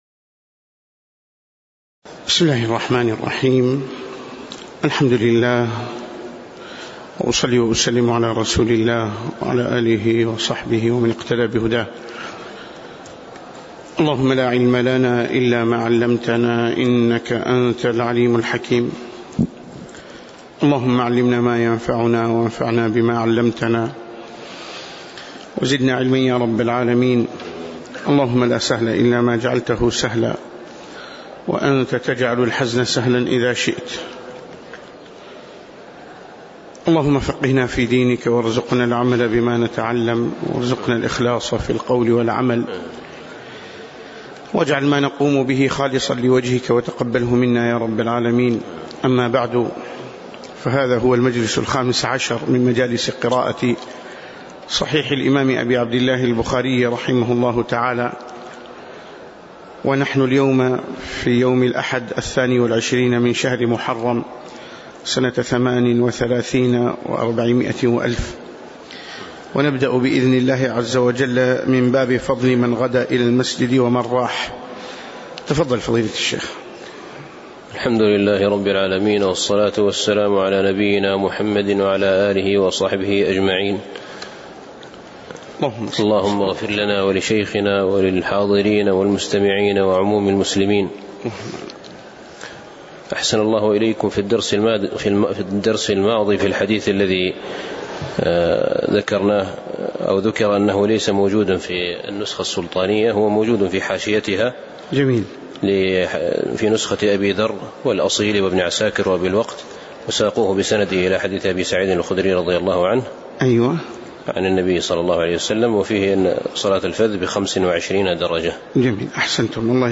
تاريخ النشر ٢٢ محرم ١٤٣٨ هـ المكان: المسجد النبوي الشيخ